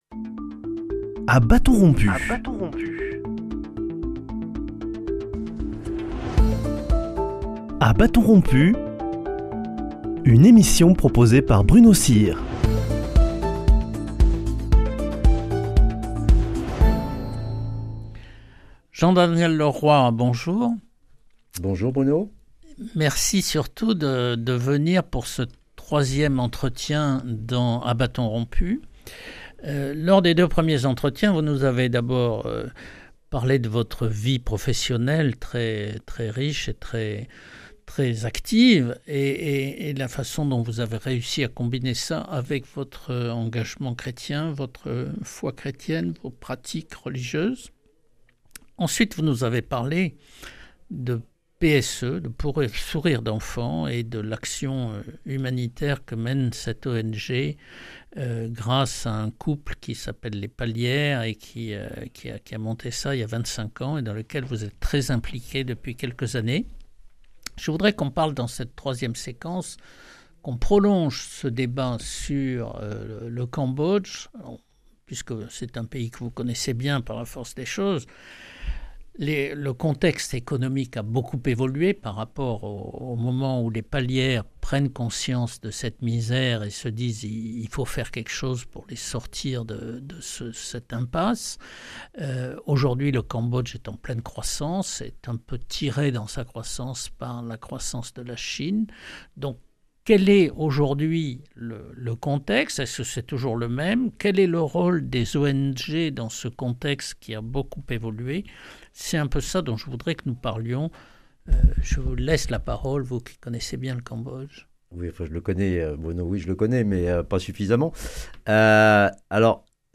Dans le dernier entretien qu’il nous a accordé, il explique comment on peut soutenir cette association. Il nous parle aussi du Cambodge aujourd’hui et du rôle des ONG dans les pays en voie de développement.